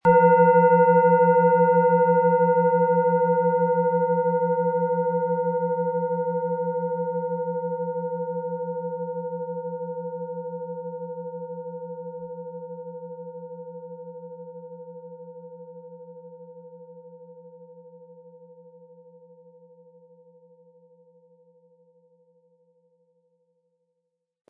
Im Sound-Player - Jetzt reinhören können Sie den Original-Ton genau dieser Schale anhören.
Ein schöner Klöppel liegt gratis bei, er lässt die Klangschale harmonisch und angenehm ertönen.
SchalenformBihar
HerstellungIn Handarbeit getrieben
MaterialBronze